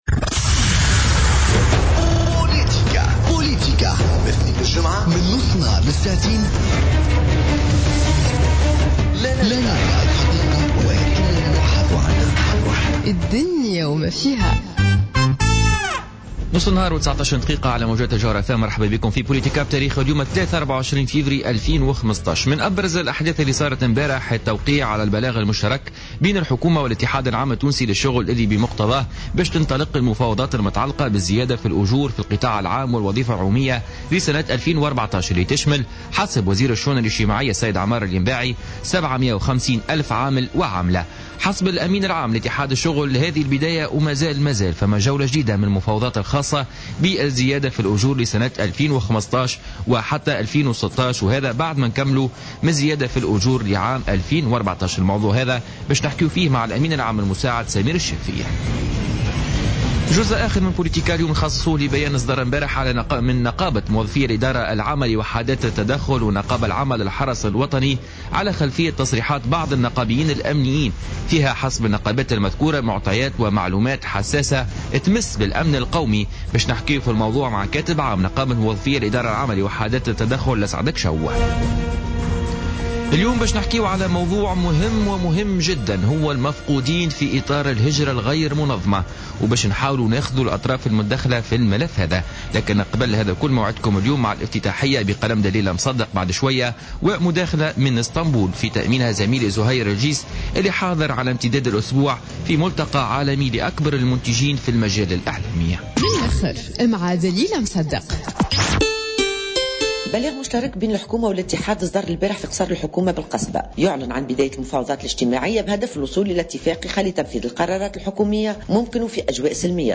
en direct d'Istanbul